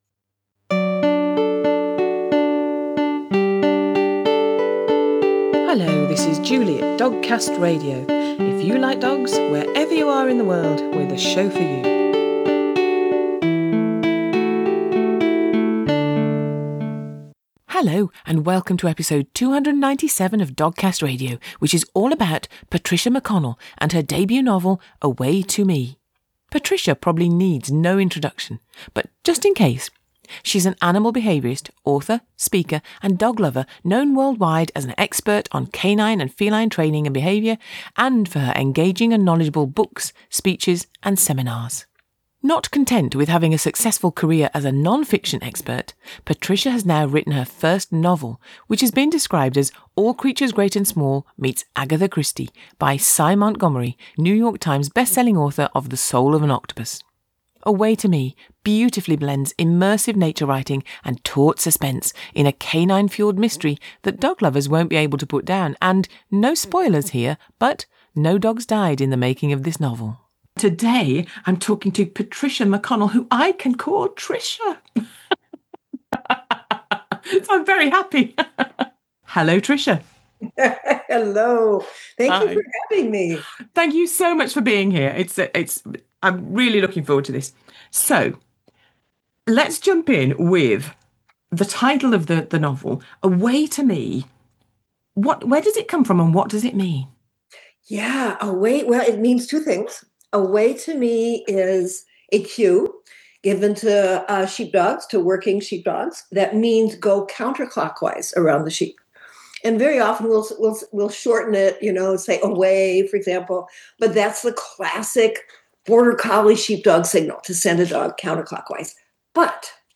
As you will hear in this interview, she's also a lovely person and lots of fun!